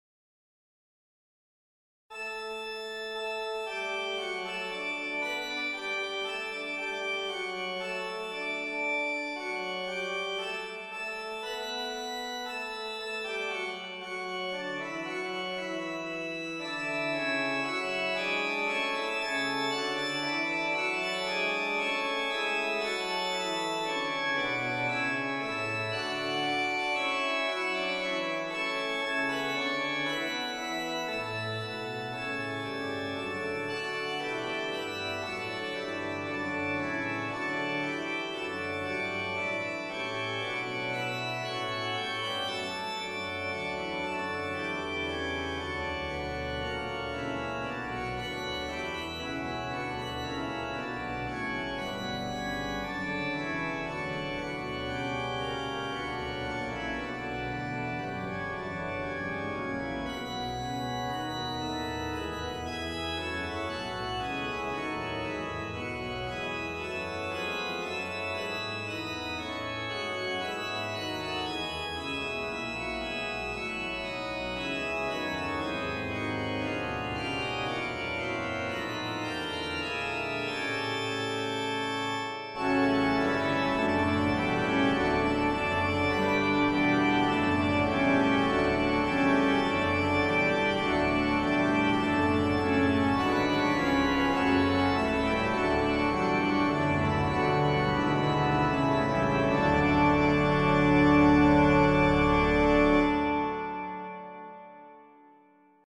also for church organ